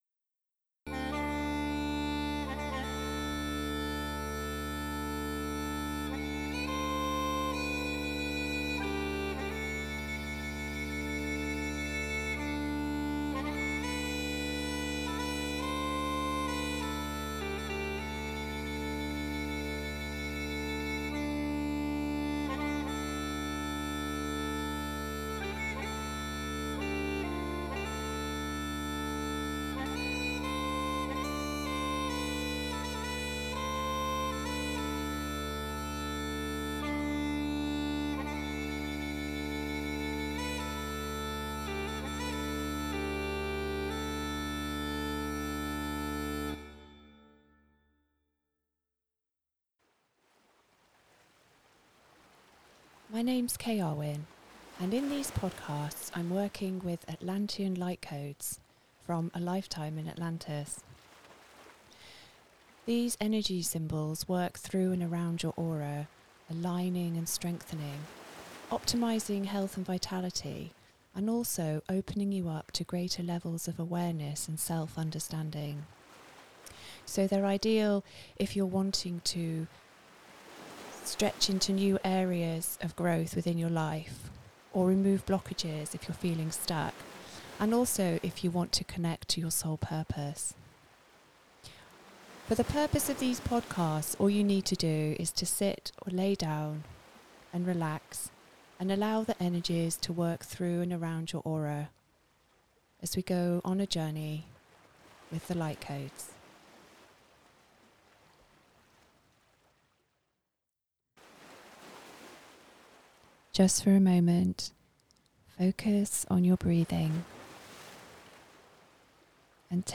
Bagpipes call you to your being. Simply sit back and listen to allow this transmission of Light Language and Atlantian Light Codes to carry you to a sacred waterfall.